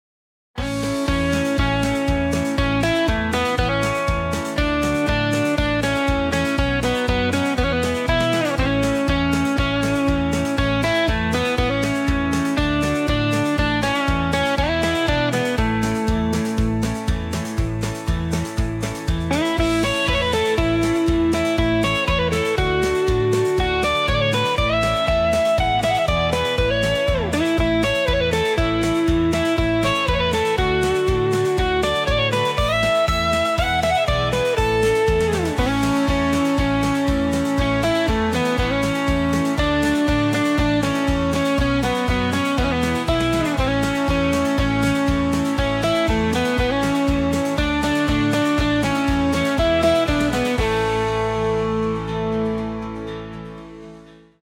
Klassisch und schwungvoll
Countrymusik